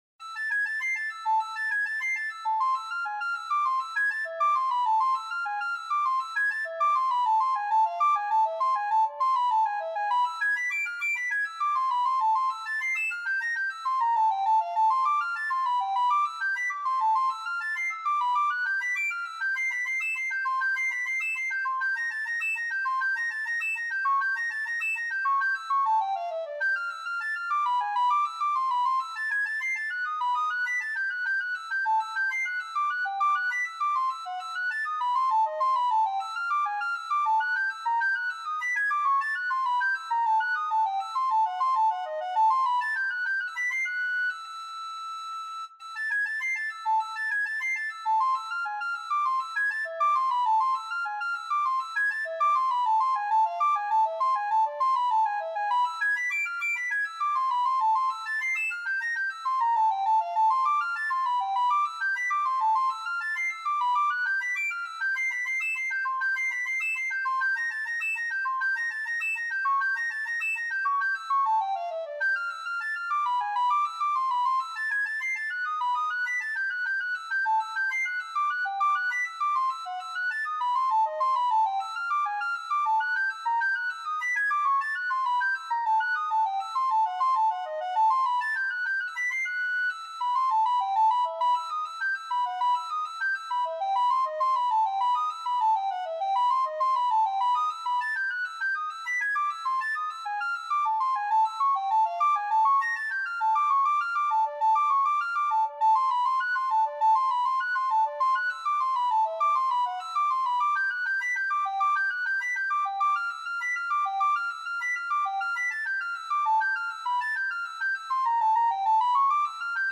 Bach, Johann Sebastian - Partita in A minor, BWV 1013 Free Sheet music for Soprano (Descant) Recorder
Time Signature: 4/4
Tempo Marking: Allemande
Style: Classical